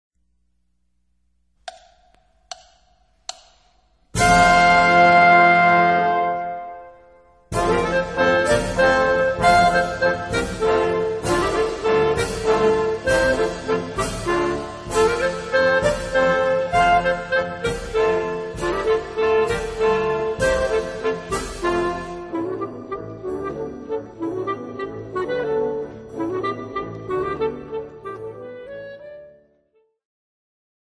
試聴サンプル